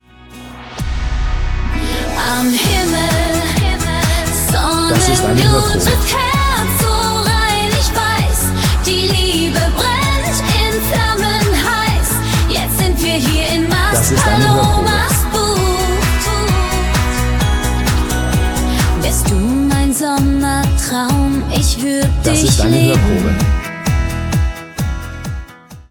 Ein Lied voller Leidenschaft und Urlaubsflair!